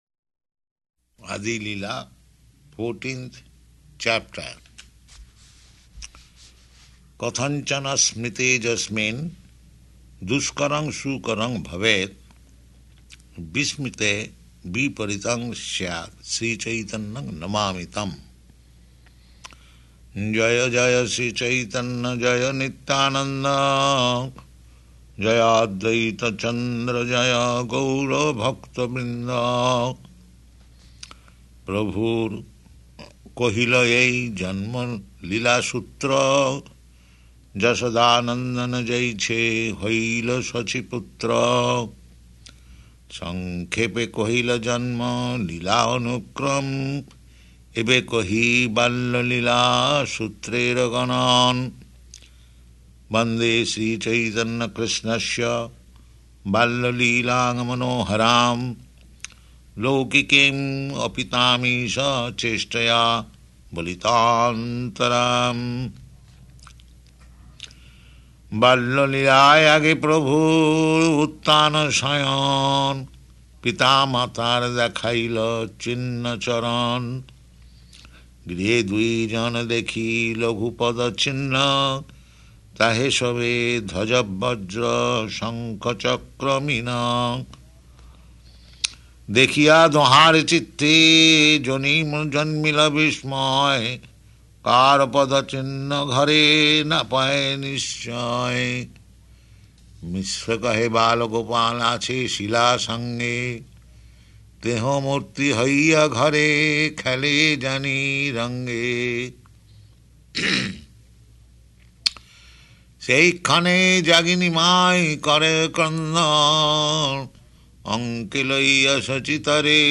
Chanting Cc Ādi-līlā 14th Chapter Verse 1–97